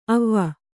♪ avva